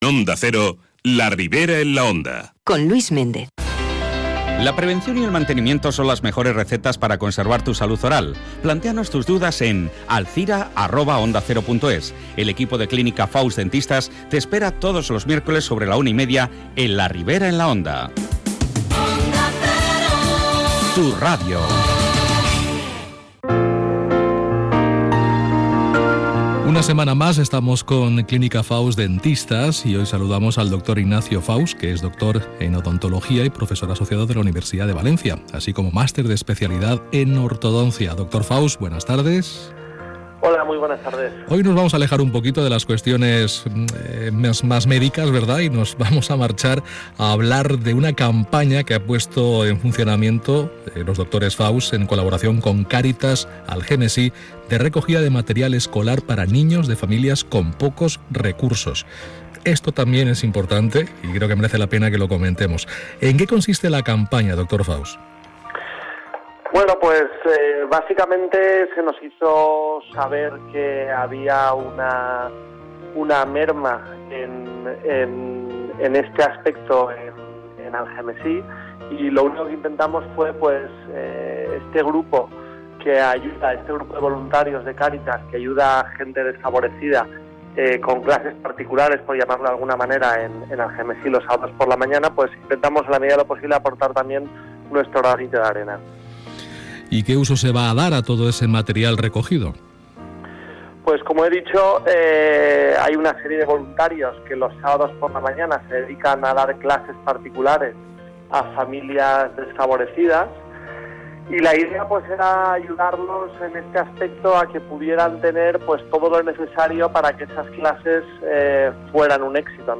Campaña Cáritas Algemesí - Entrevista Onda Cero